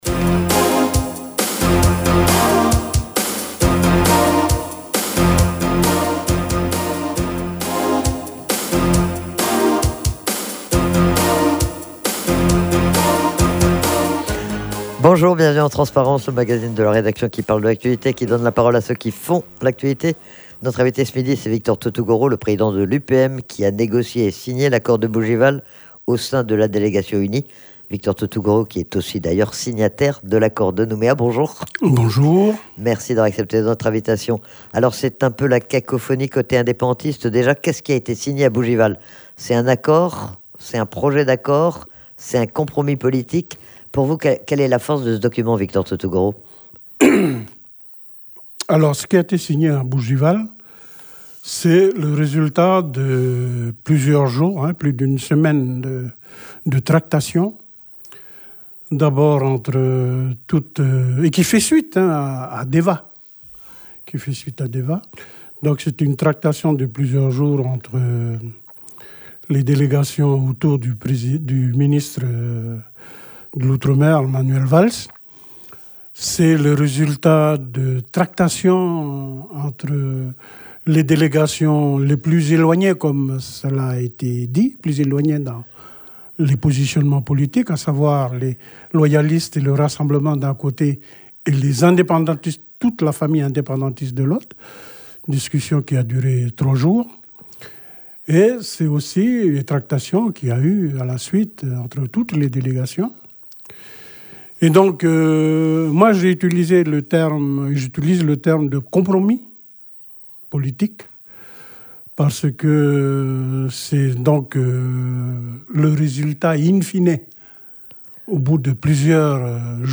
Il a participé aux négociations au sein de la délégation UNI, Victor Tutugoro a été interrogé sur le contenu de ce document, sur les raisons pour lesquelles il l'a signé, et sur les réactions qu'il suscite, notamment, dans le camp indépendantiste.